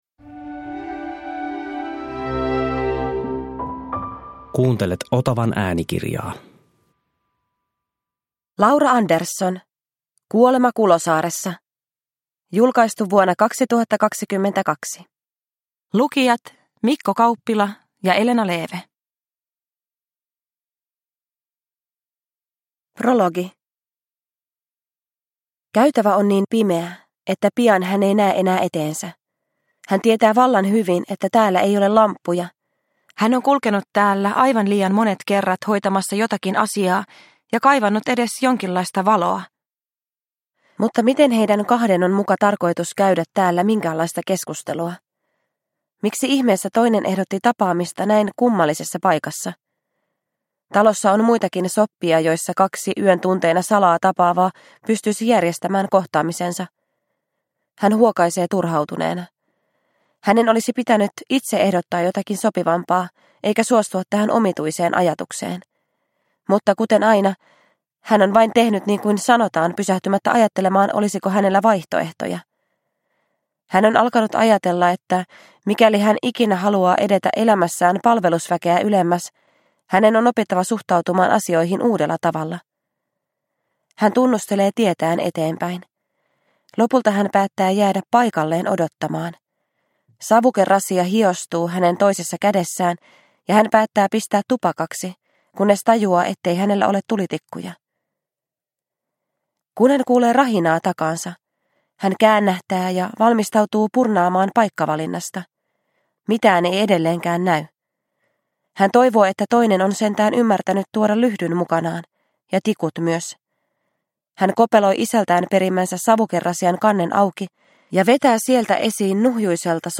Kuolema Kulosaaressa – Ljudbok – Laddas ner